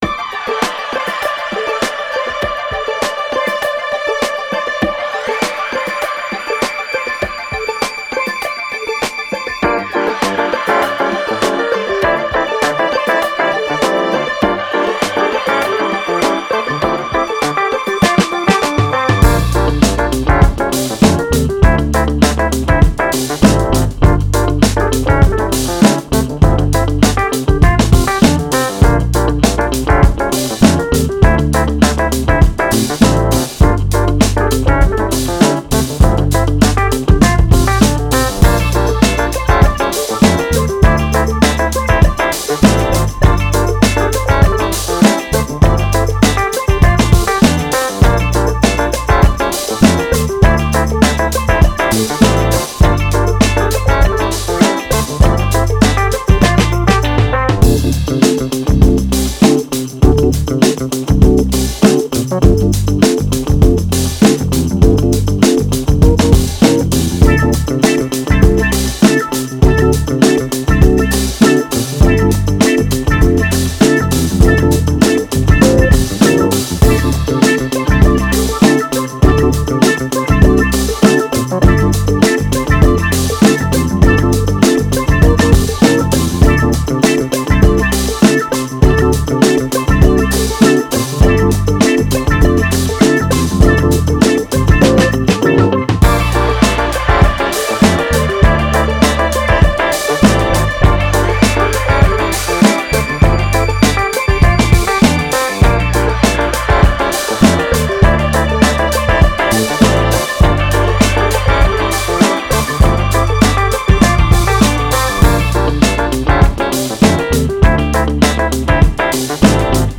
Soul, Upbeat, Positive, Happy, Joy